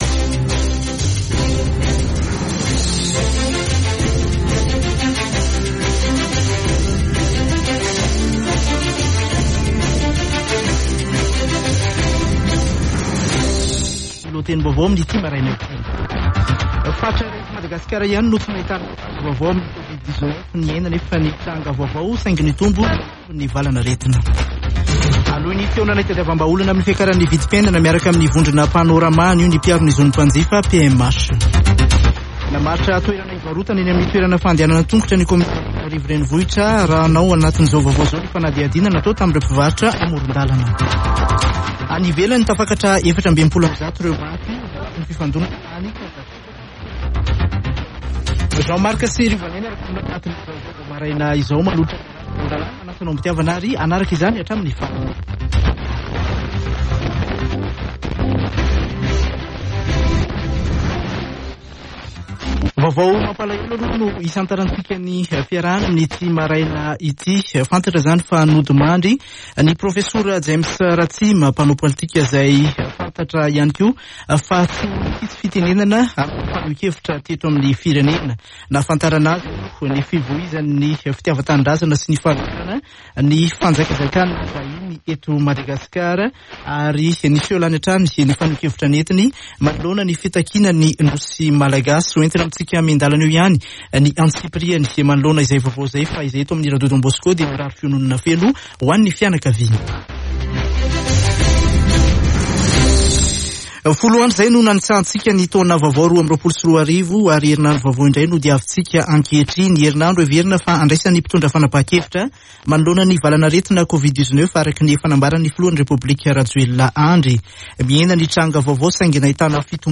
[Vaovao maraina] Alatsinainy 10 janoary 2022